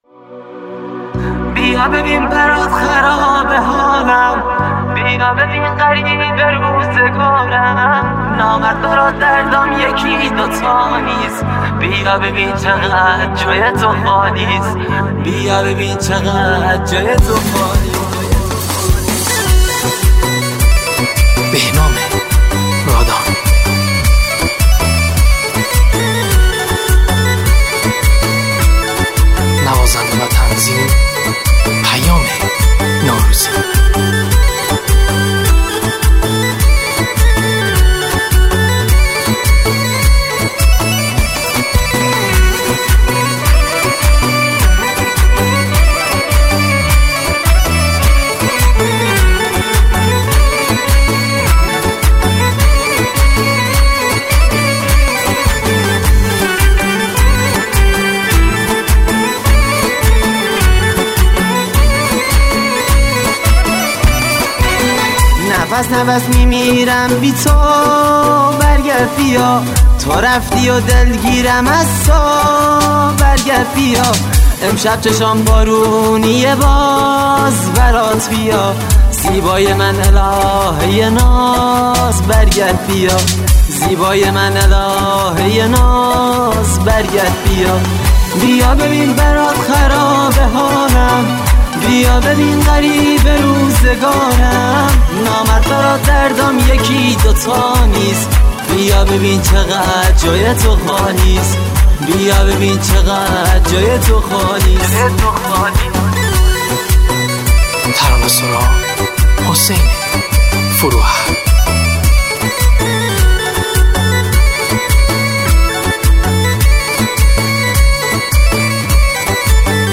آهنگ جدید کرمانجی